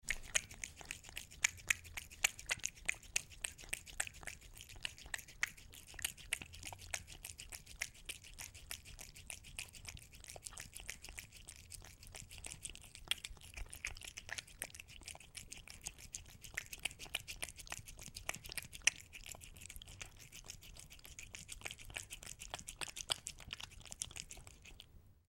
Громкий звук облизывания тарелки вблизи